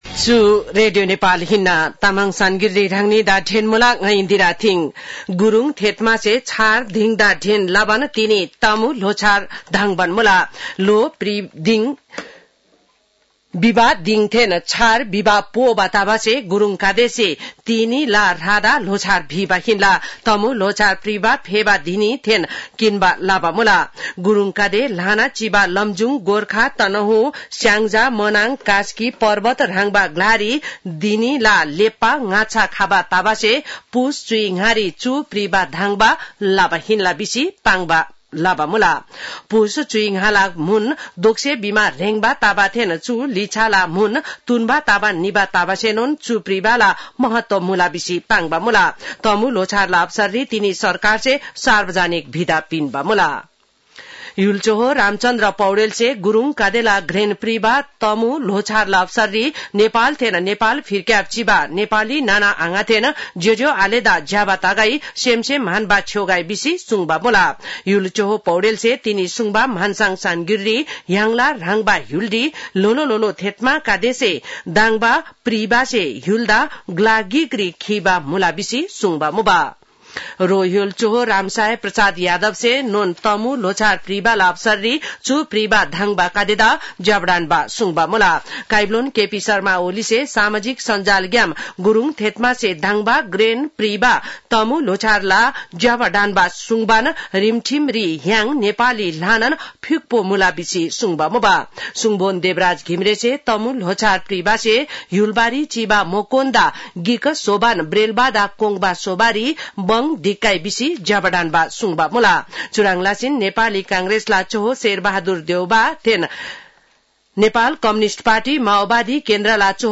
तामाङ भाषाको समाचार : १६ पुष , २०८१